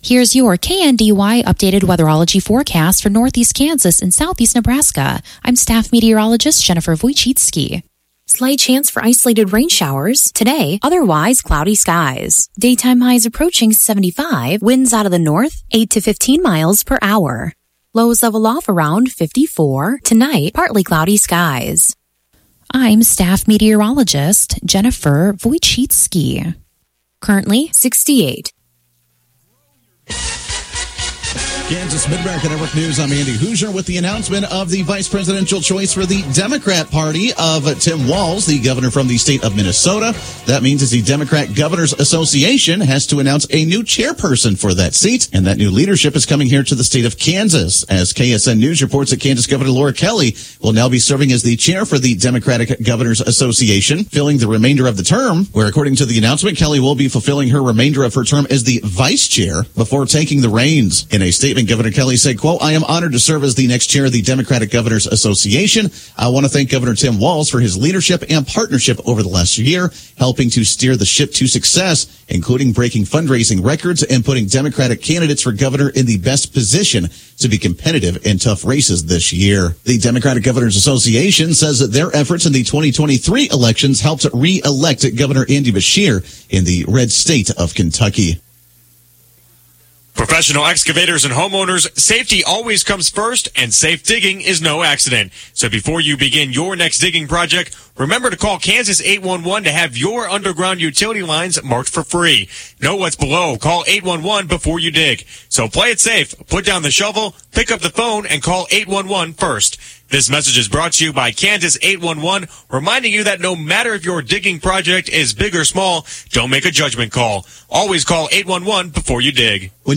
KNDY Morning News - 8/8/2024